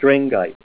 Say STRENGITE Help on Synonym: Synonym: Barrandite   Globosite